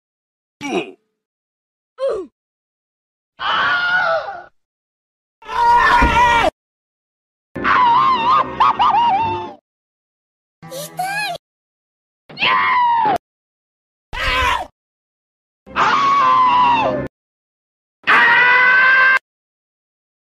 OUCH sound effects
Thể loại: Âm thanh meme Việt Nam
ouch-sound-effects-www_tiengdong_com.mp3